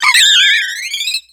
Cri d'Étourvol dans Pokémon X et Y.